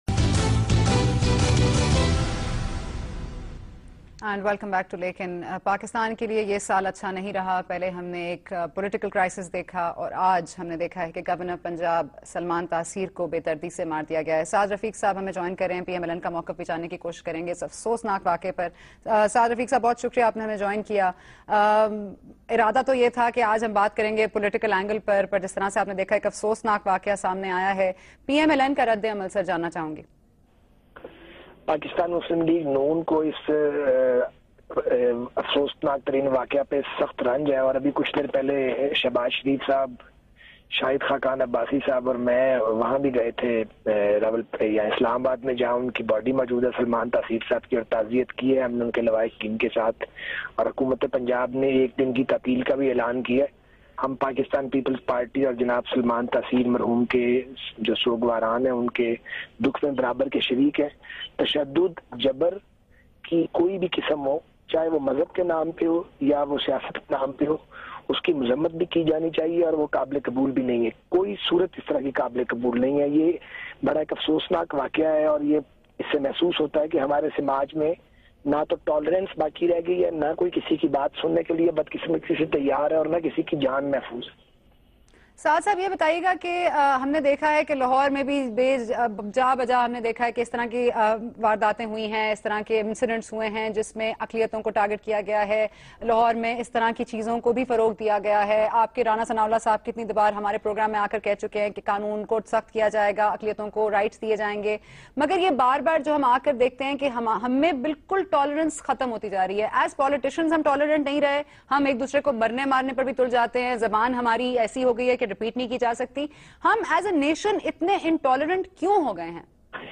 Javed Ahmad Ghamdi expresses his grief and comments on murder of Governor Punjab Salman Taseer in program Lakin on Geo New. Host: Sana Bucha